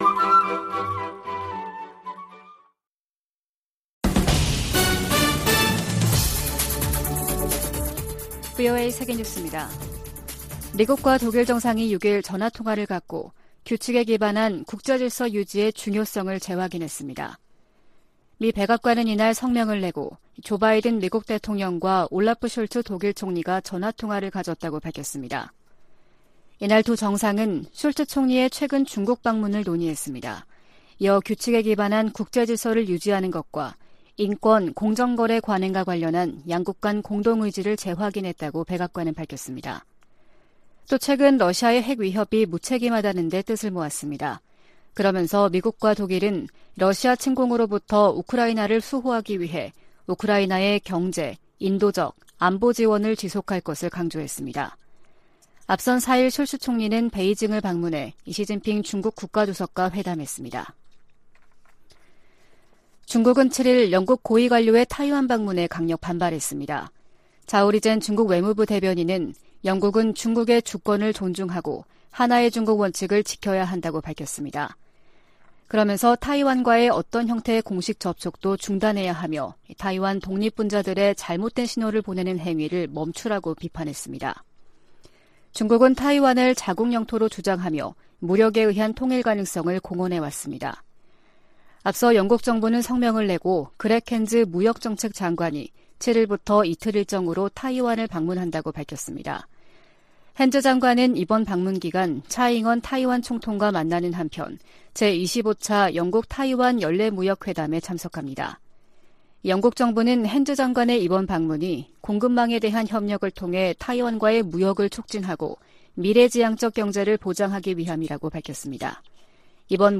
VOA 한국어 아침 뉴스 프로그램 '워싱턴 뉴스 광장' 2022년 11월 8일 방송입니다. 북한이 미한 연합공중훈련 ‘비질런트 스톰’에 대응한 자신들의 군사작전 내용을 대내외 매체를 통해 비교적 상세히 밝혔습니다. 유엔 안보리가 대륙간탄도미사일(ICBM)을 포함한 북한의 최근 탄도미사일 발사에 대응한 공개회의를 개최하고 북한을 강하게 규탄했습니다.